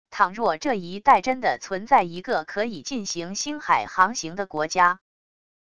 倘若这一带真的存在一个可以进行星海航行的国家wav音频生成系统WAV Audio Player